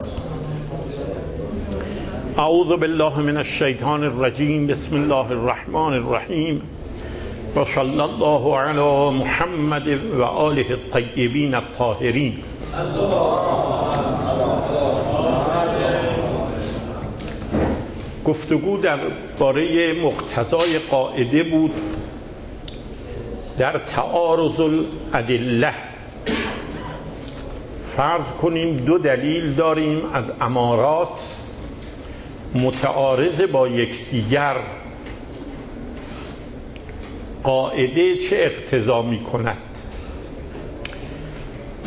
پخش صوت درس: